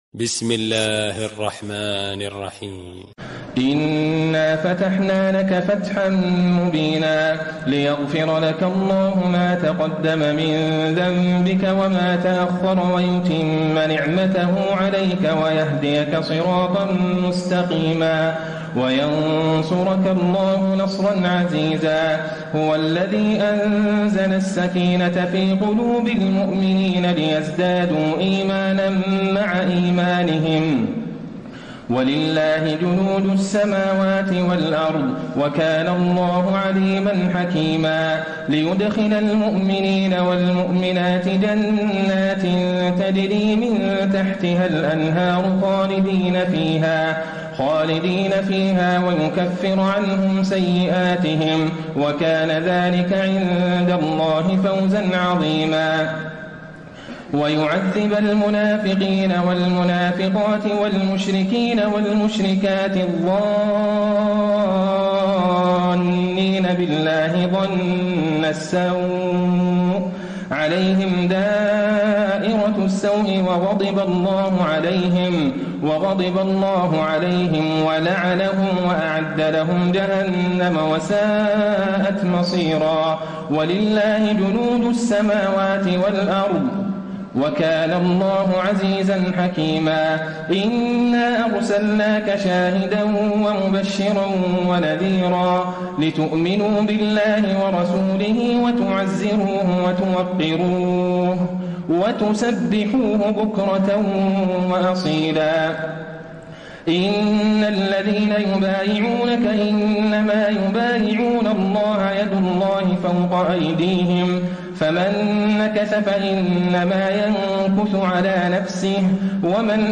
تراويح ليلة 25 رمضان 1434هـ من سور الفتح الحجرات و ق Taraweeh 25 st night Ramadan 1434H from Surah Al-Fath and Al-Hujuraat and Qaaf > تراويح الحرم النبوي عام 1434 🕌 > التراويح - تلاوات الحرمين